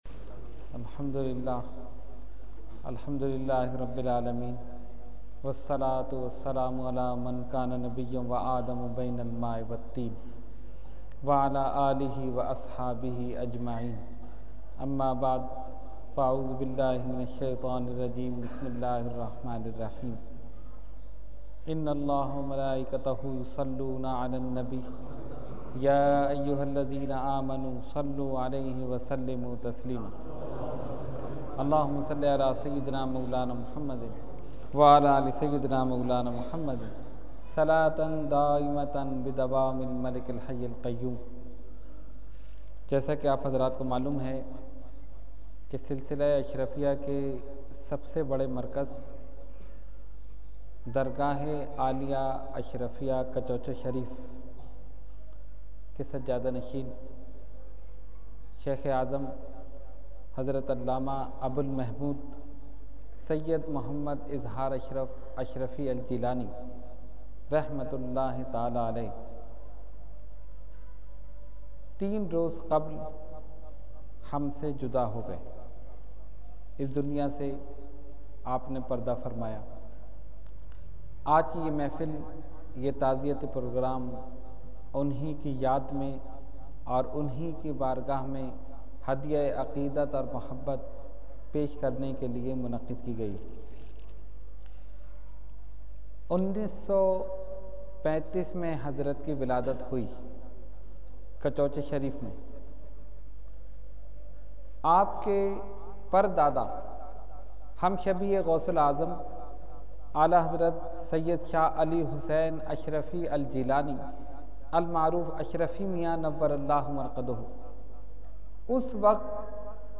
Category : Speech